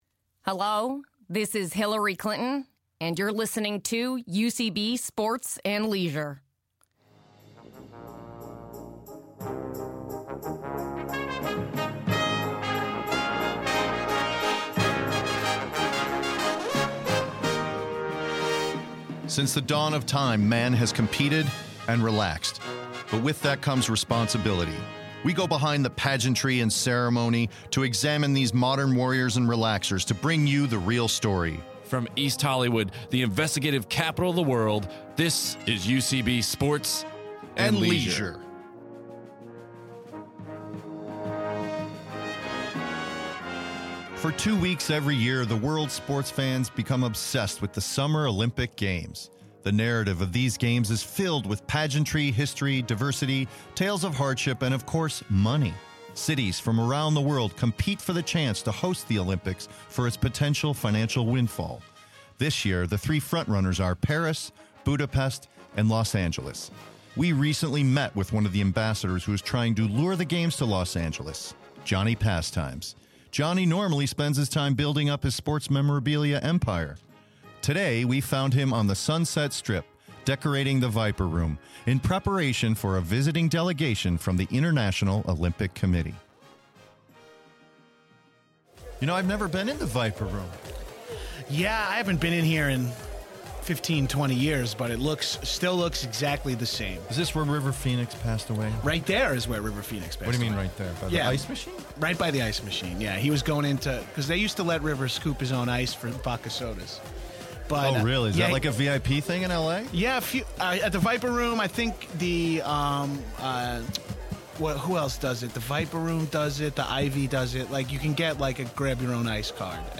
In this preliminary Olympic special, UCB Sports & Leisure’s Matt Walsh & Scot Armstrong spend a week with Las Vegas sports memorabilia king-turned-Olympic-Committee liaison, Johnny Pastimes (Jon Gabrus). Recorded on-location in Los Angeles, Matt & Scot follow Johnny as he seeks to win the affection of the International Olympic Committee Members in hopes of hosting the 2024 games in Los Angeles.